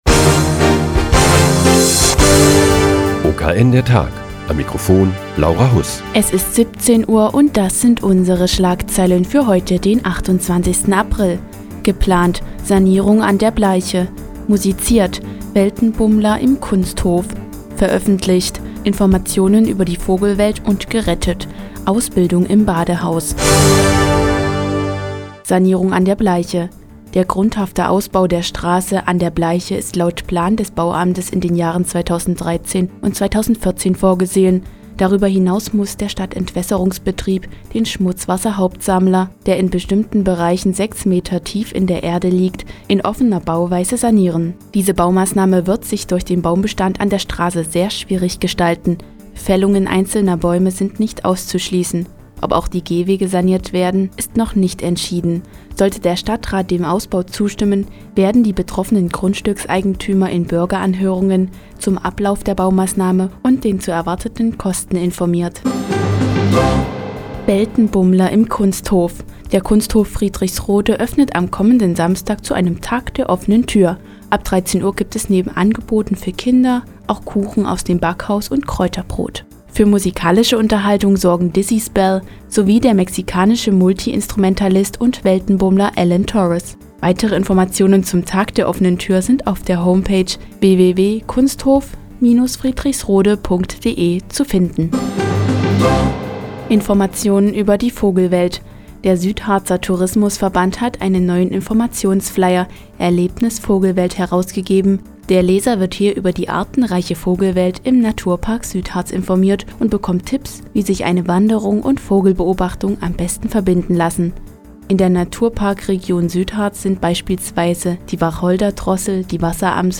Die tägliche Nachrichtensendung des OKN ist nun auch in der nnz zu hören. Heute geht es um den grundhaften Ausbau der Straße "An der Bleiche", den "Tag der offenen Tür" im Kunsthof Friedrichsrode und eine Rettungsschwimmerausbildung im Badehaus Nordhausen.